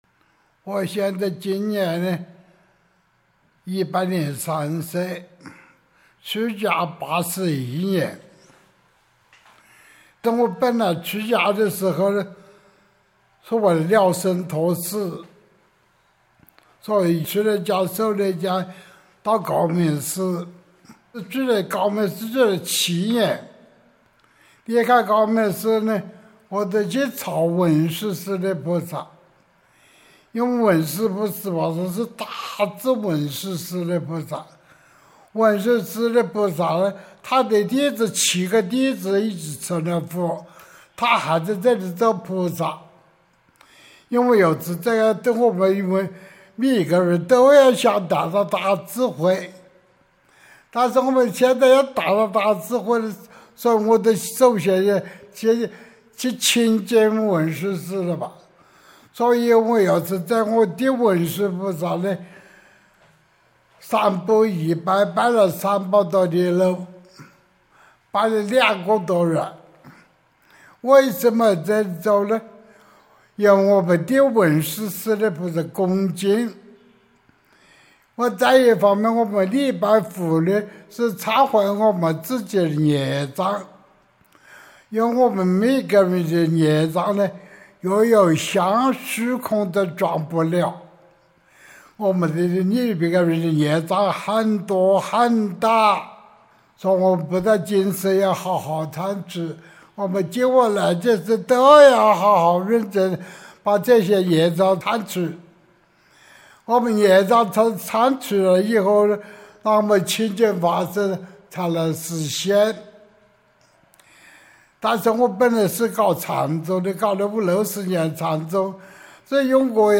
本焕长老念佛开示
诵经